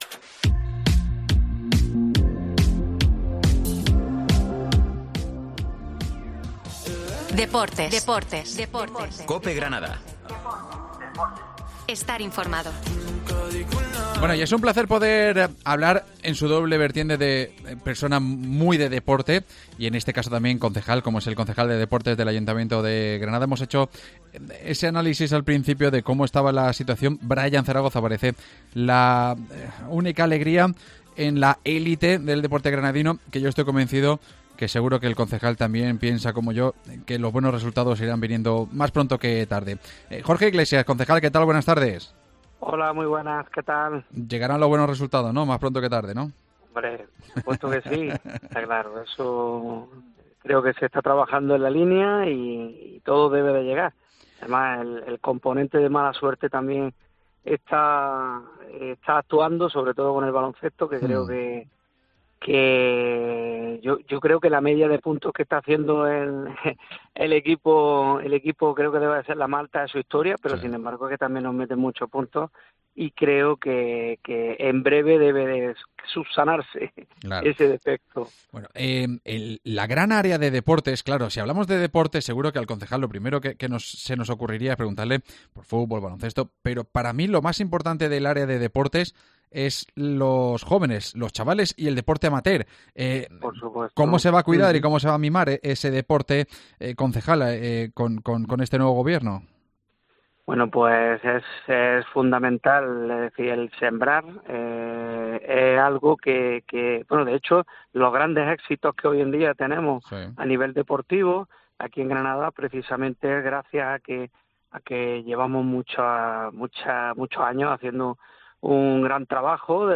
AUDIO: Entrevista con Jorge Iglesias, concejal de deportes del Ayuntamiento de Granada